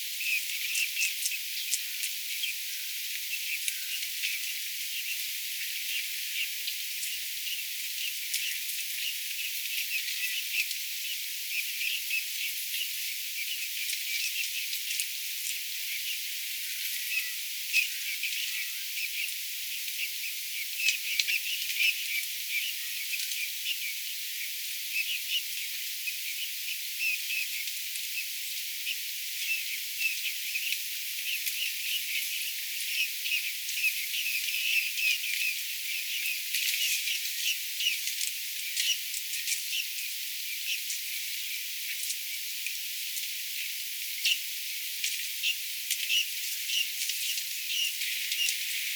viiksitimalien lähiääntelyä, 3
viiksitimalien_ehka_vahan_punatulkkumaisia_lahiaania_viiksitimaleita_lahella_ruovikossa.mp3